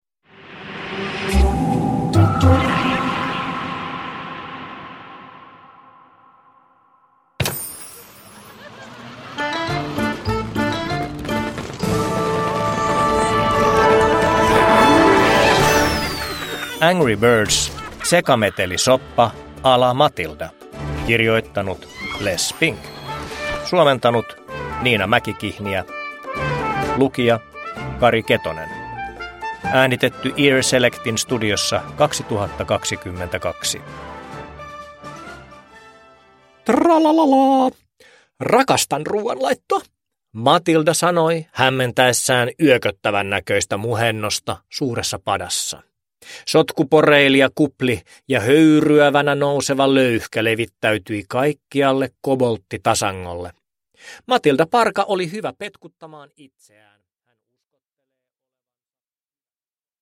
Angry Birds: Sekametelisoppaa a´ la Matilda (ljudbok) av Les Spink